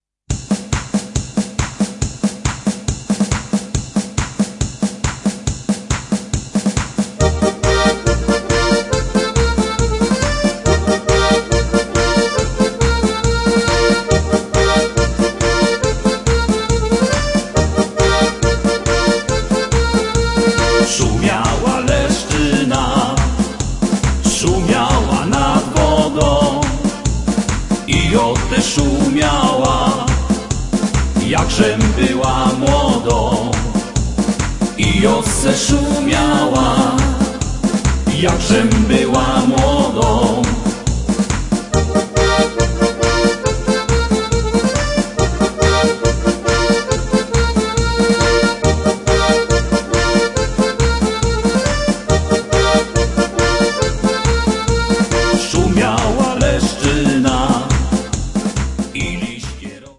Vocals
Accordion
Percussion
Guitars